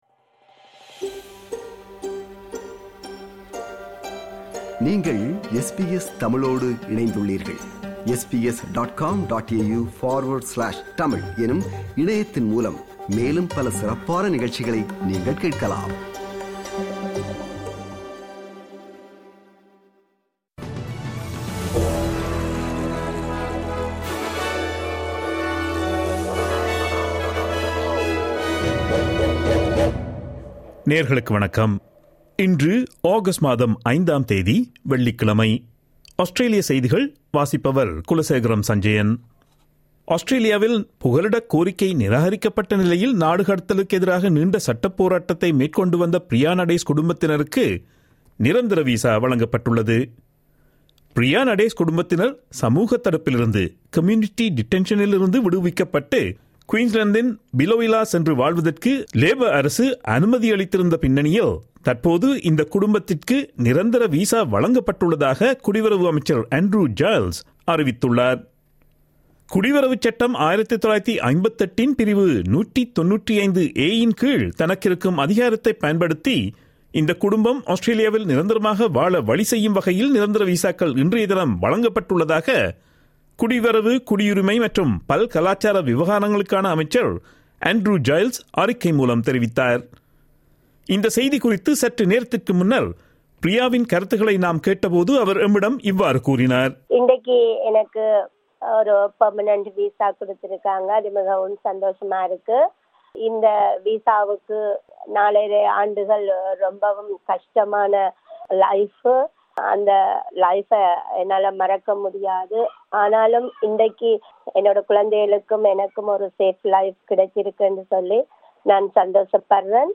Australian news bulletin for Friday 05 August 2022.